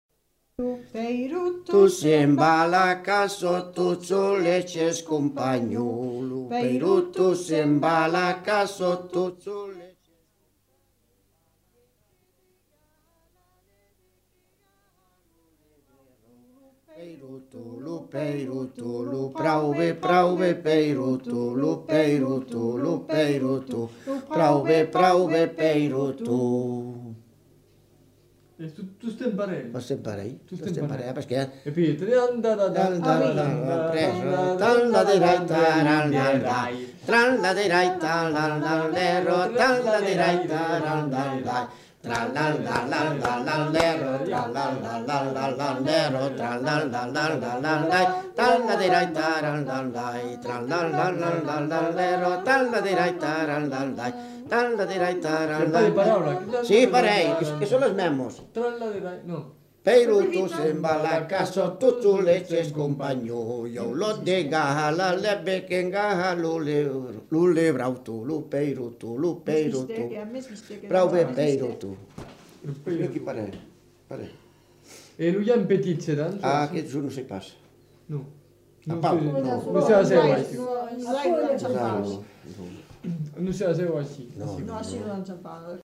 Aire culturelle : Béarn
Genre : chant
Type de voix : voix d'homme ; voix de femme
Production du son : chanté
Danse : peiroton (saut)
Notes consultables : Sous-modulation (un passage est inaudible).